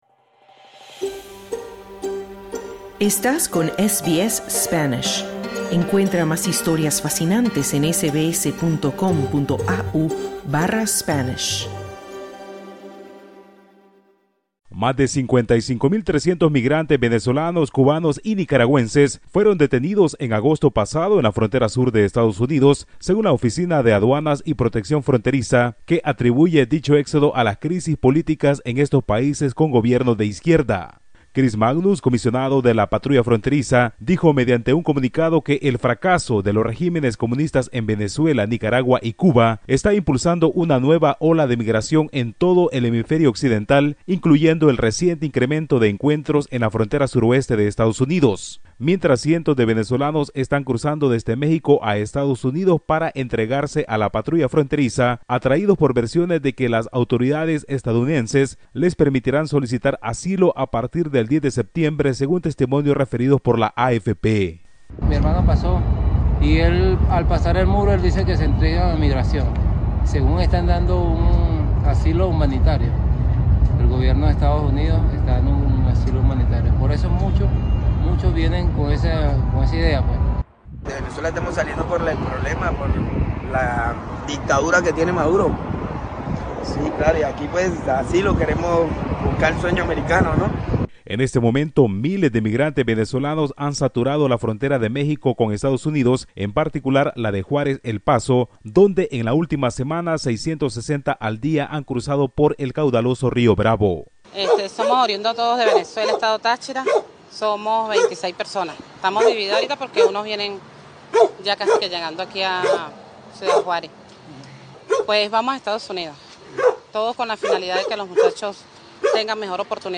Escucha el informe del corresponsal de SBS Spanish en Latinoamérica